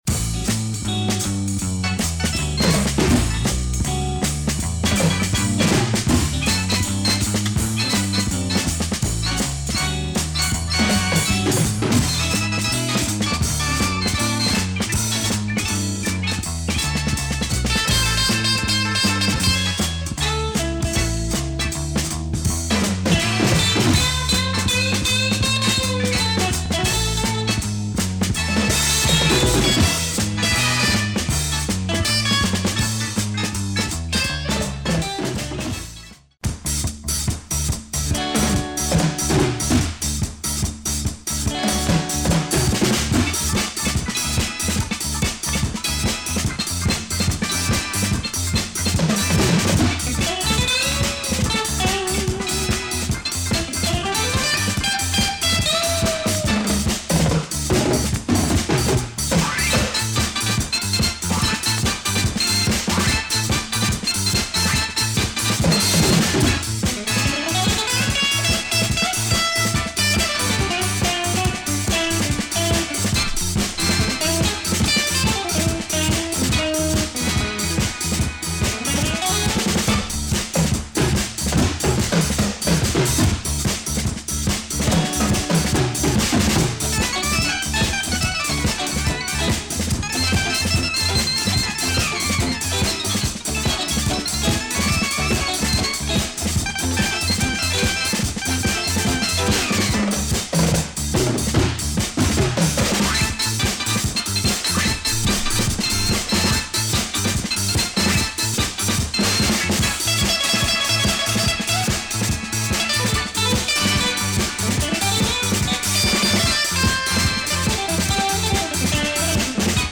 I had troubles finding this magnificent Indian soundtrack.